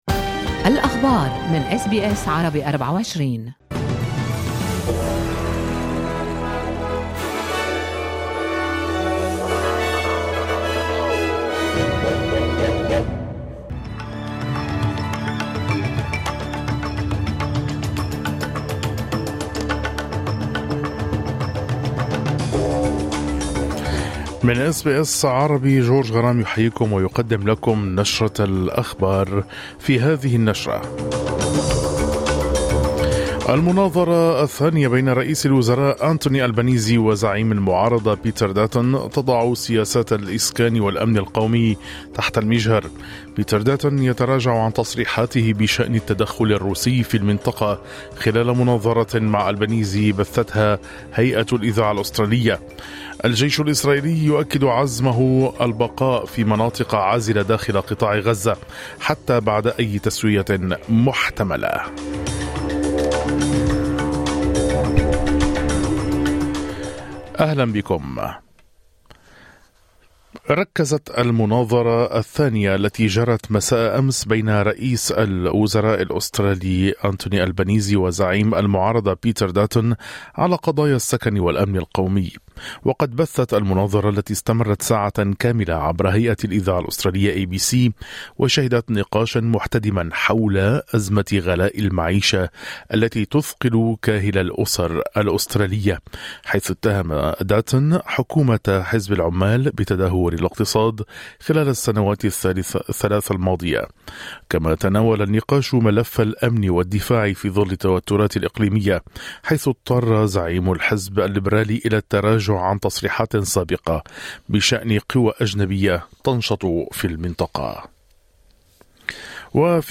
نشرة أخبار الصباح 17/04/2025